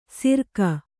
♪ sirka